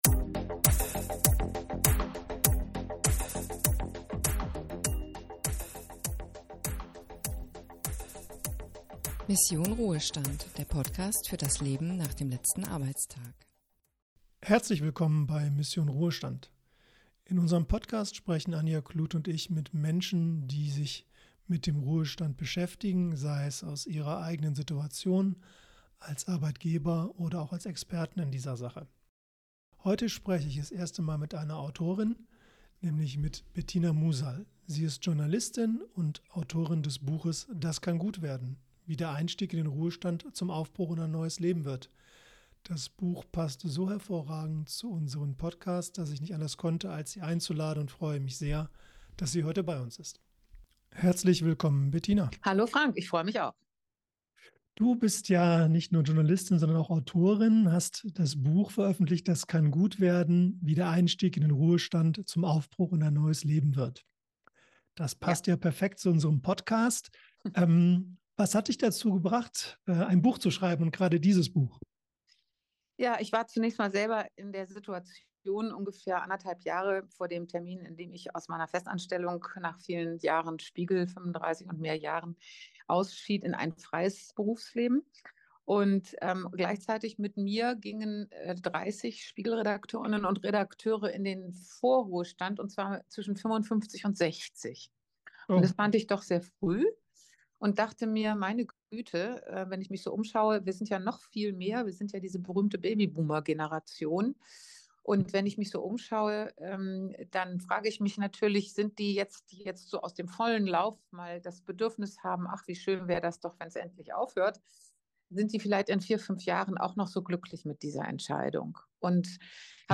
Autorengespräch